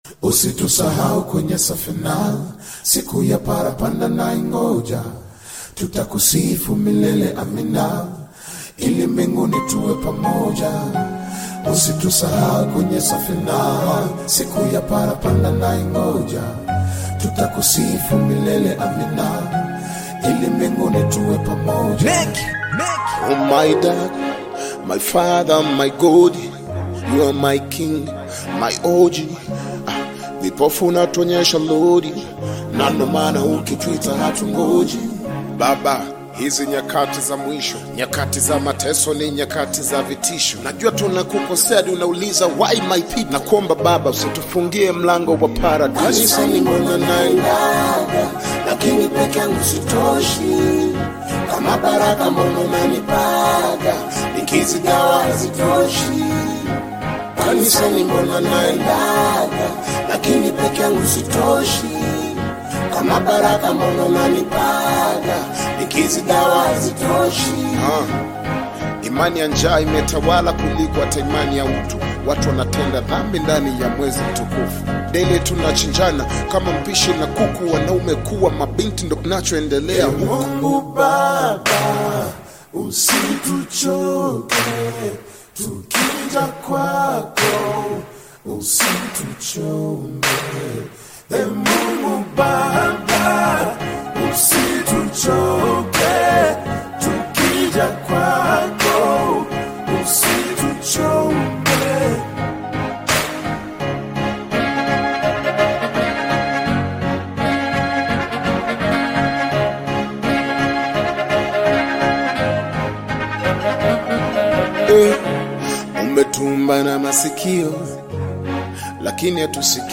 Bongo Flava
African Music